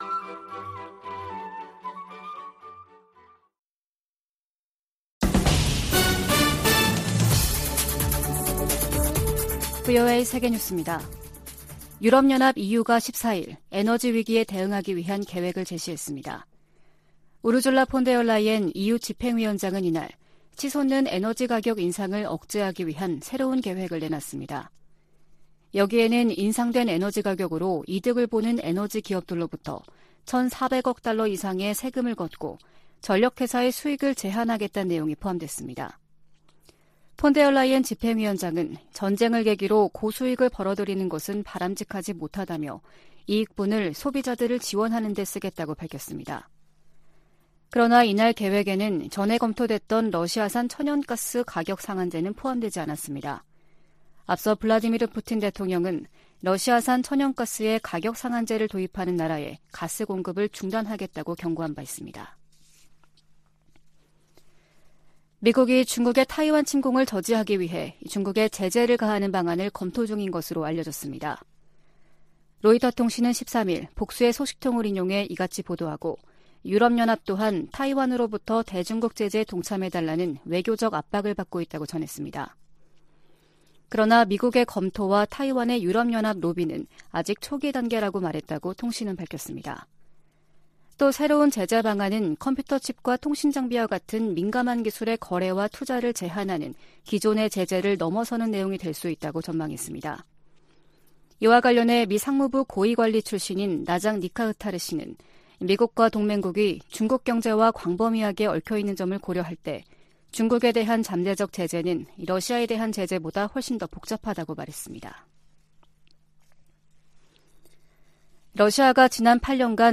VOA 한국어 아침 뉴스 프로그램 '워싱턴 뉴스 광장' 2022년 9월 15일 방송입니다. 북한의 핵 무력정책 법제화가 유일지배체제의 근본적 모순을 드러내고 있다고 전문가들이 분석하고 있습니다. 미 상원의원들이 북한의 핵 무력정책 법제화에 우려와 비판의 목소리를 내고 있습니다. 유엔 인권기구가 북한 지도부의 코로나 규제 조치로 강제노동 상황이 더 악화했을 수 있다고 경고했습니다.